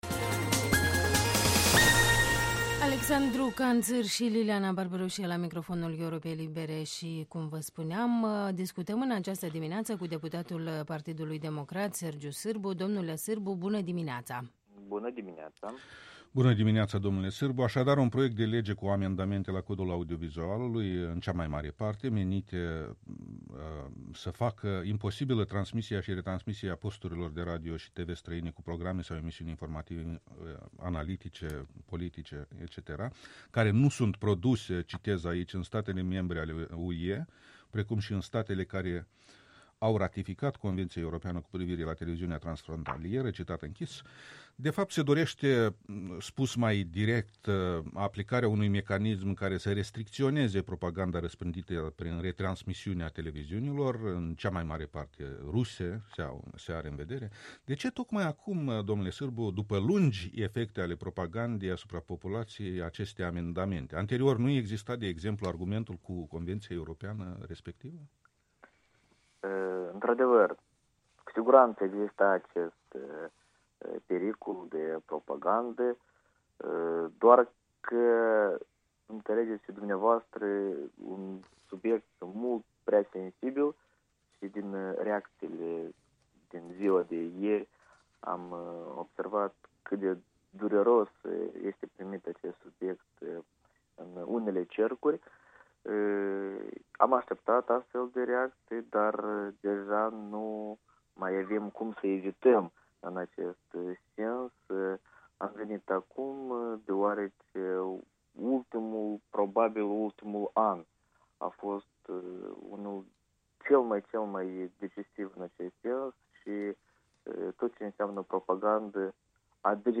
Interviul matinal la EL: cu deputatul PD Sergiu Sîrbu